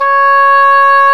Index of /90_sSampleCDs/Roland L-CDX-03 Disk 1/CMB_Wind Sects 1/CMB_Wind Sect 2
WND ENGHRN0E.wav